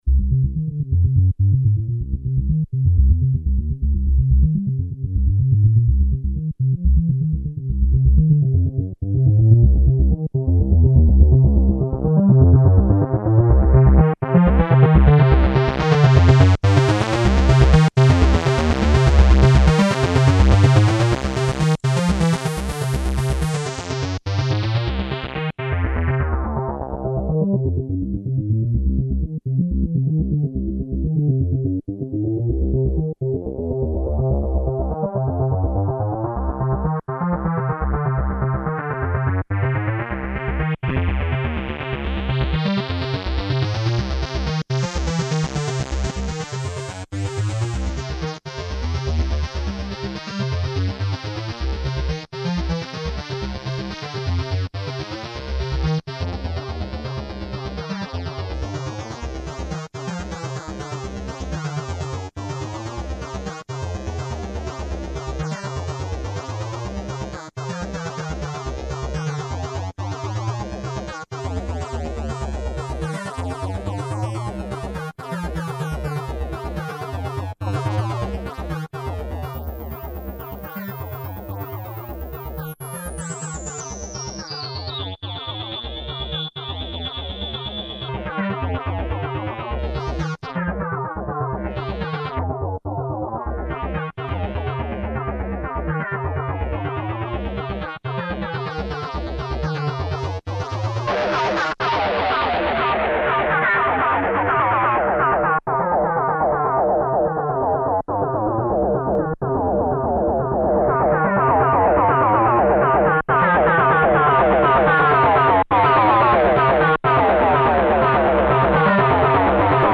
No external effects added
A fast monophonic sequence. There is a hardware guitar overdrive stompbox in pre-filter insert where the demo starts to scream. Hardware and software components play together in this demo:
Monophonic. Overdriven and filtered (2.92MB)